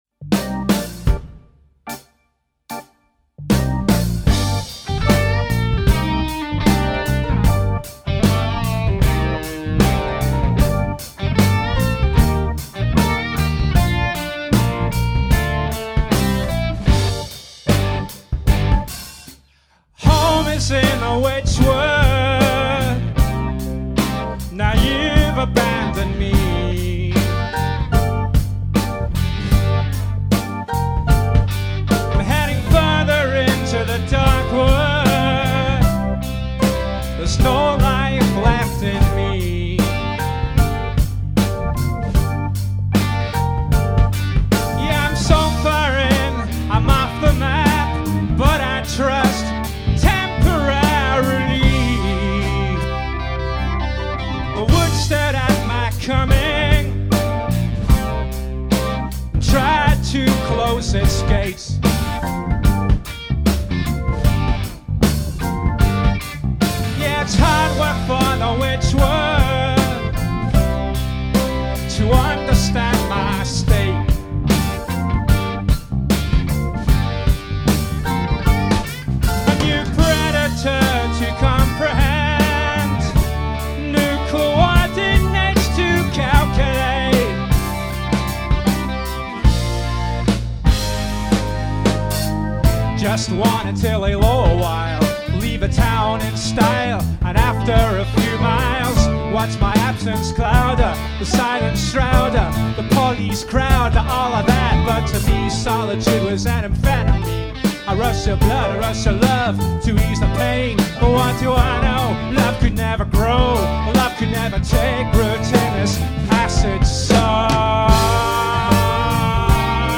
a 5-piece band
a rehearsal room recording